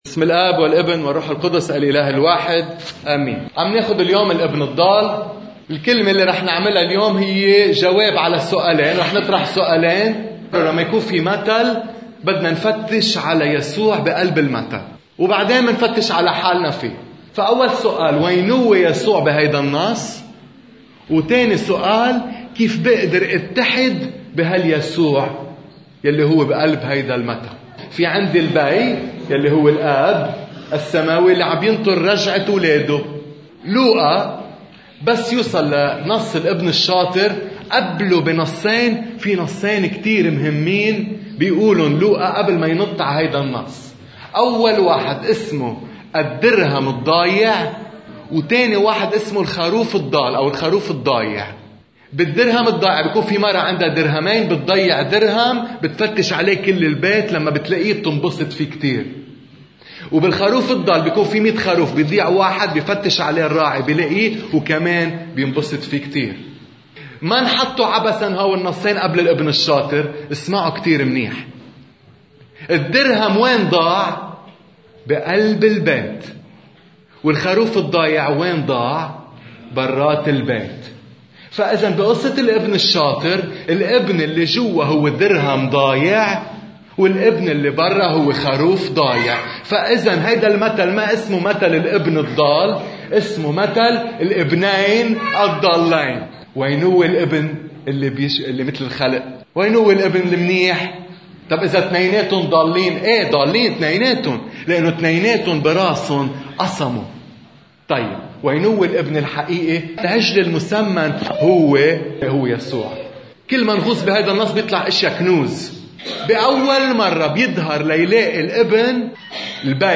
عظة أحد الآباء بخصوص الإبنين الضالين إنطلاقاً من مثل الإبن الشاطر…